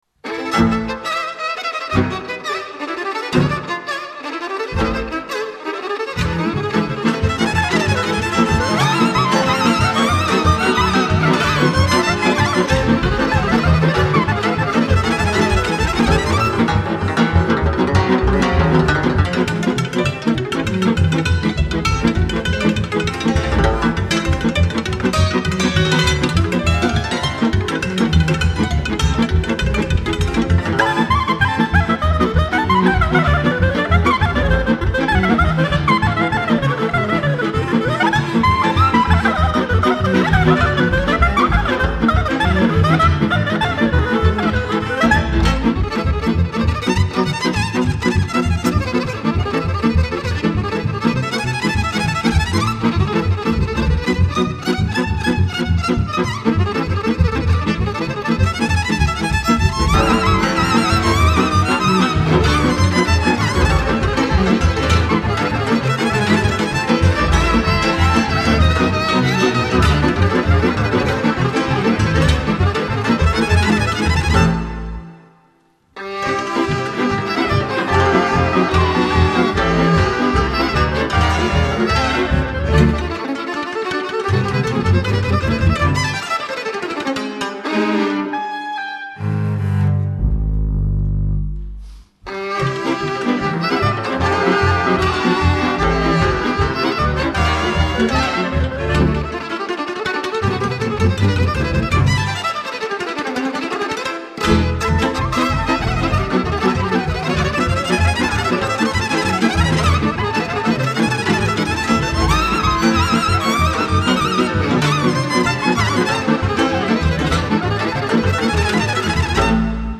Zimbal
instrumental